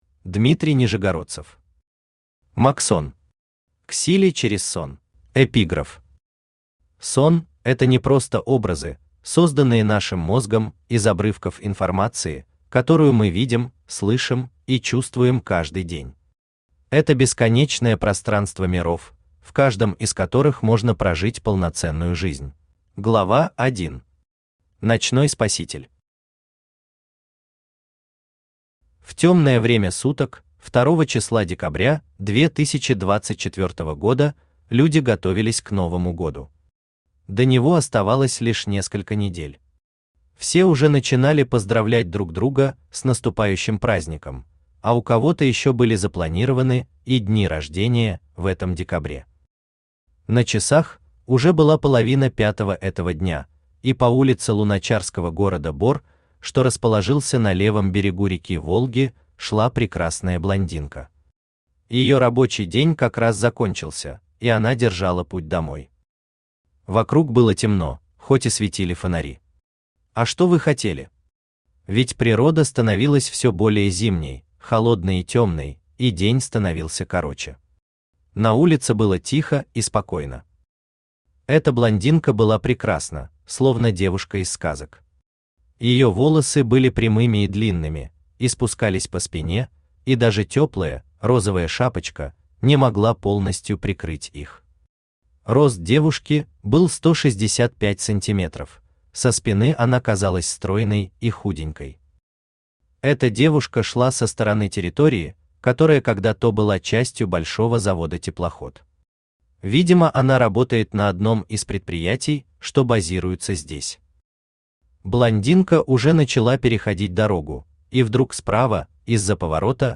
Аудиокнига Магсон. К силе через сон | Библиотека аудиокниг
К силе через сон Автор Дмитрий Юрьевич Нижегородцев Читает аудиокнигу Авточтец ЛитРес.